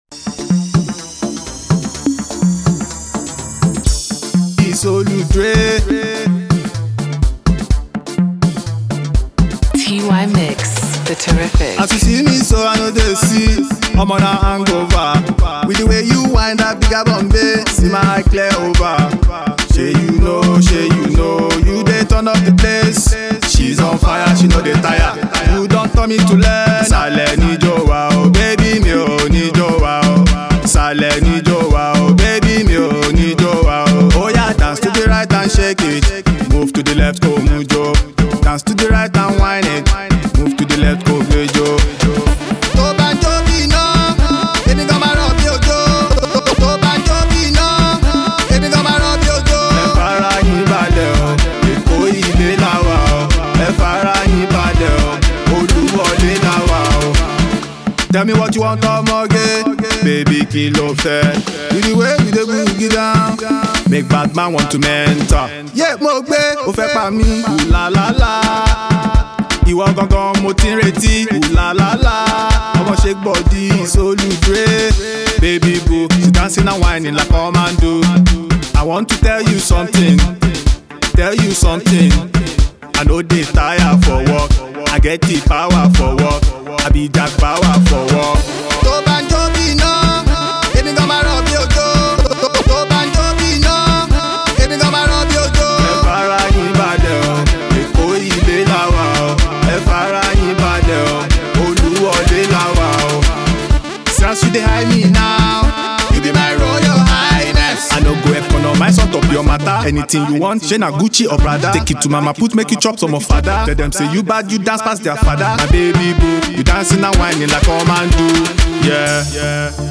Alternative Pop
Fuji hip hop